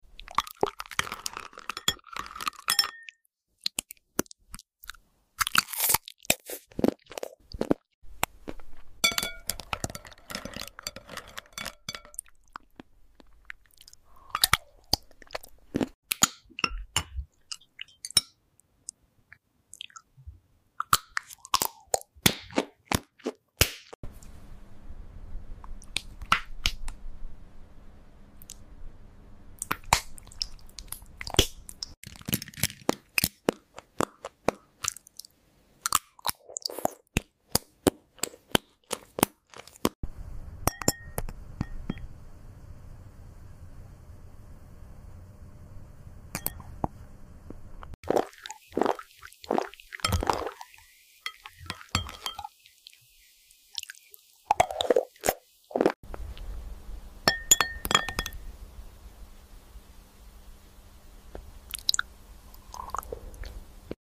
🥣🔊 Soup ASMR with Crunch sound effects free download
🥣🔊 Soup ASMR with Crunch – The perfect mix of steamy slurps and crispy bites. Every spoon hits different: soft broth, loud crunch, total satisfaction.